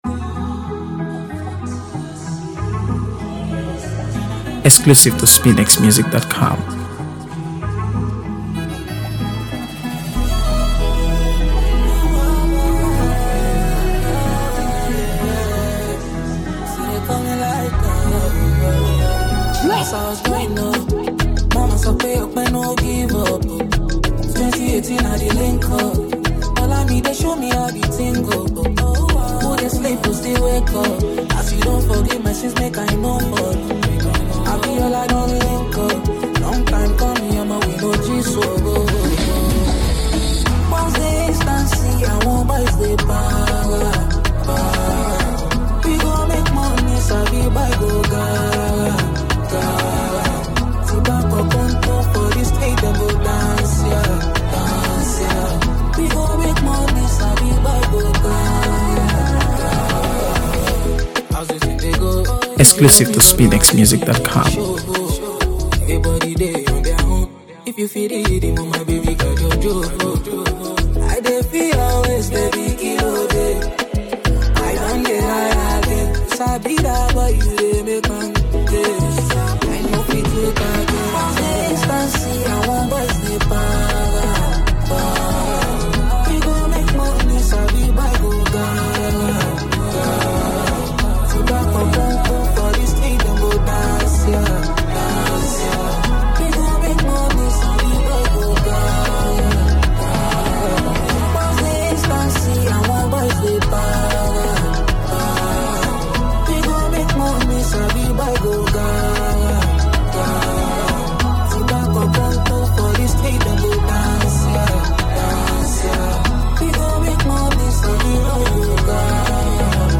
AfroBeats | AfroBeats songs
With its infectious beat and captivating lyrics
seamless blend of Afrobeat and contemporary sounds
catchy beat, meaningful lyrics, and vibrant energy